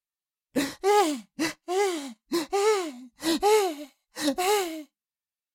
Cartoon Little Monster, Voice, Crying 2 Sound Effect Download | Gfx Sounds
Cartoon-little-monster-voice-crying-2.mp3